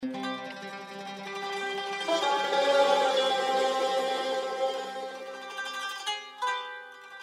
زنگ هشدار پیامک